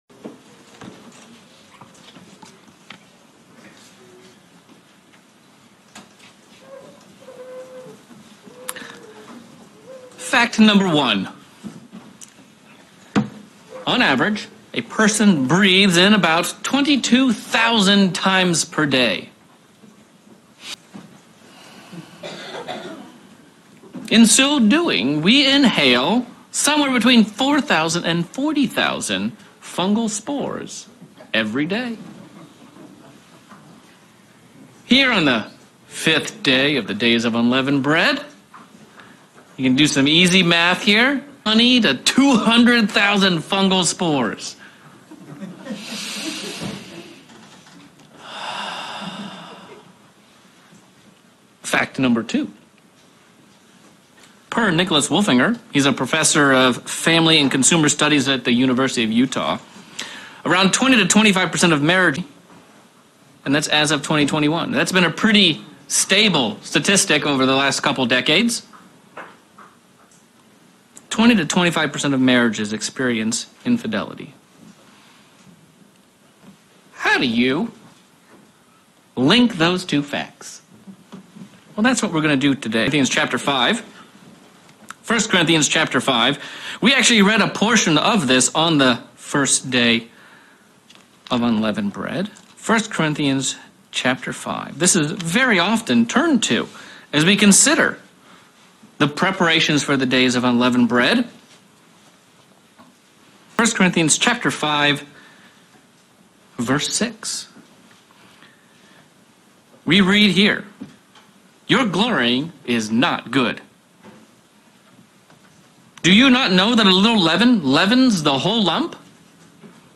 Sabbath sermon looking at the lessons we learn during the days of Unleavened Bread and using them to help us build a strong and faithful relationship with God